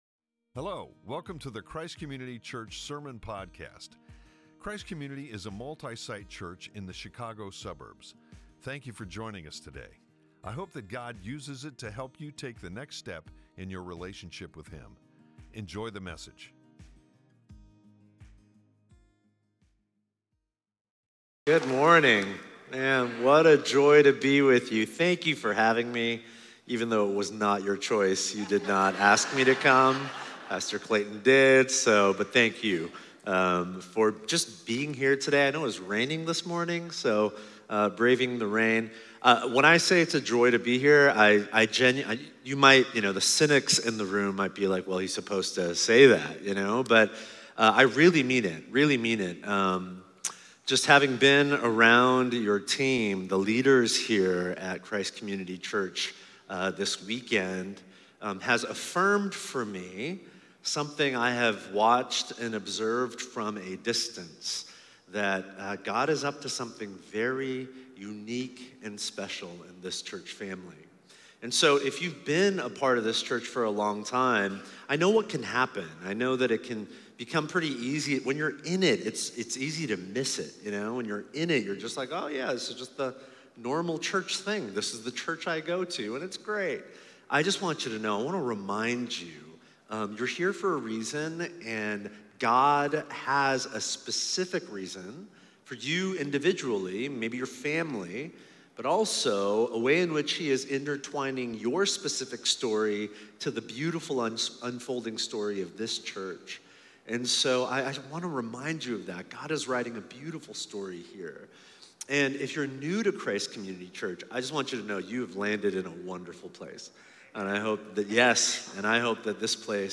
7-20-25-Sermon.mp3